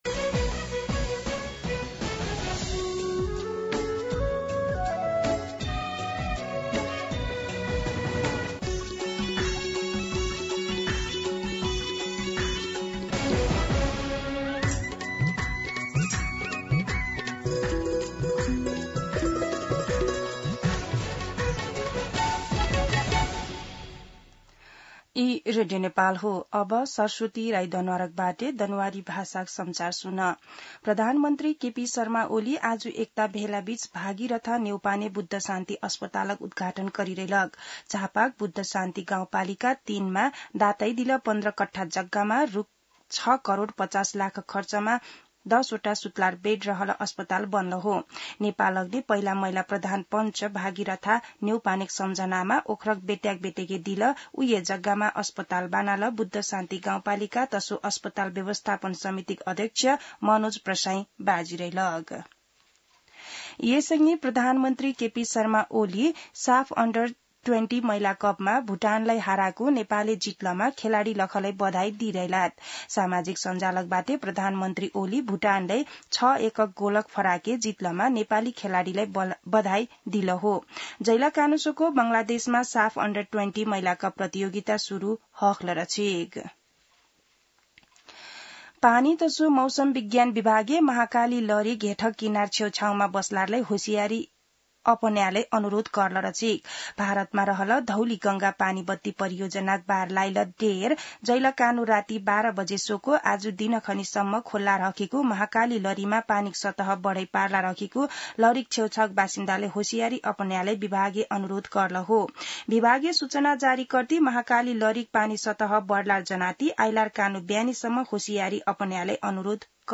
दनुवार भाषामा समाचार : २८ असार , २०८२
Danuwar-News-1-1.mp3